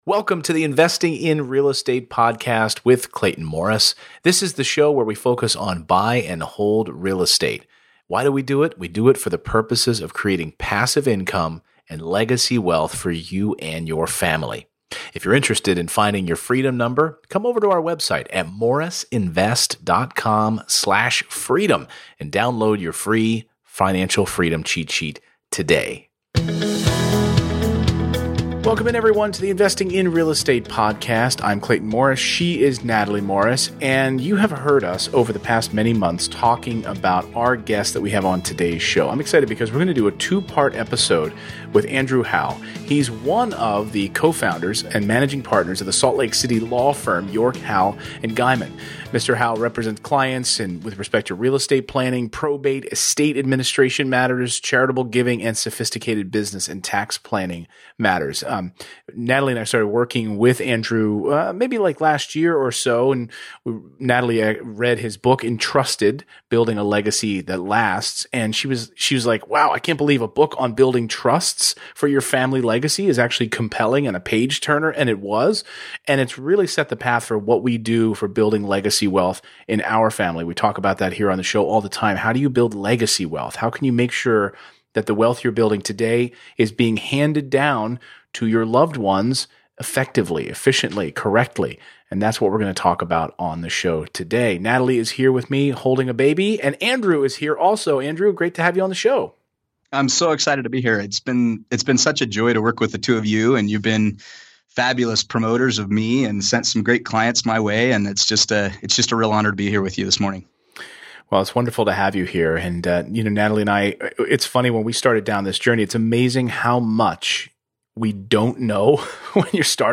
EP167: Basic Estate Planning for Legacy Wealth Building - Interview